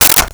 Plastic Plate 02
Plastic Plate 02.wav